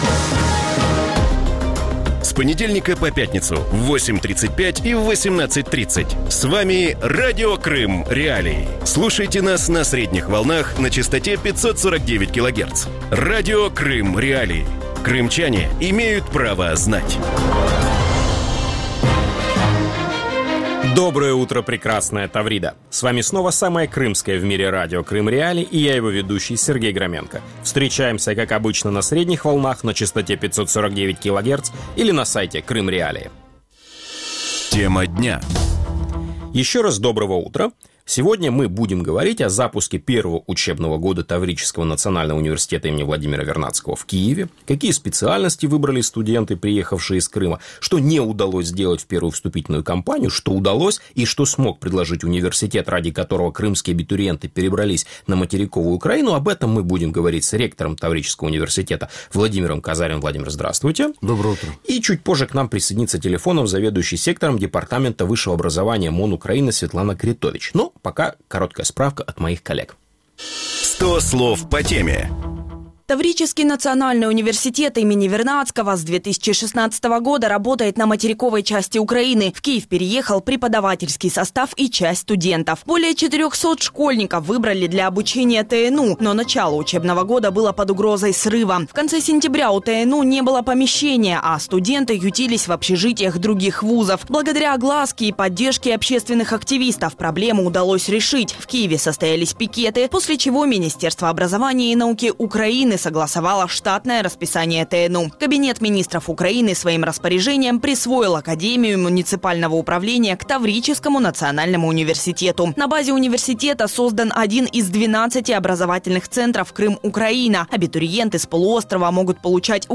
Вранці в ефірі Радіо Крим.Реалії говорять про старт першого навчального року Таврійського національного університету ім.Вернадського в Києві. Що не вдалося зробити в першу вступну кампанію? Що зміг запропонувати університет, заради якого абітурієнти приїхали з Криму до Києва?